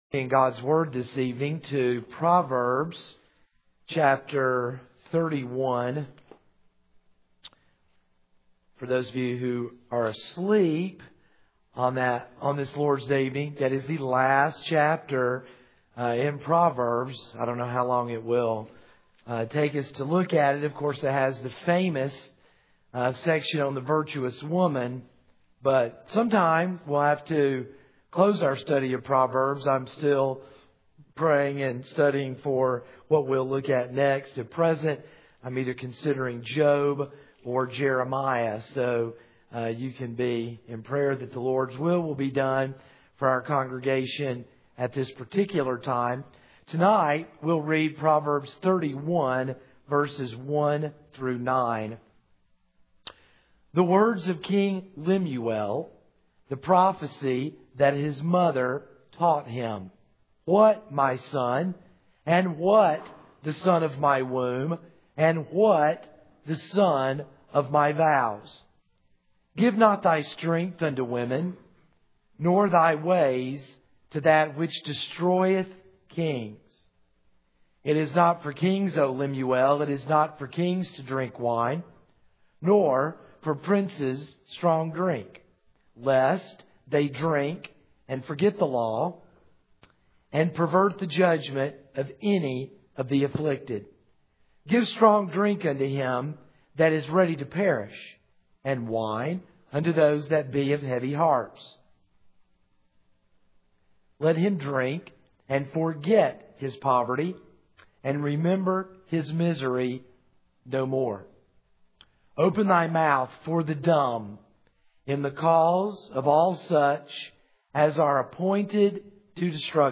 This is a sermon on Proverbs 31:1-9.